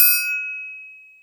SouthSide Small Trap Bell (1).wav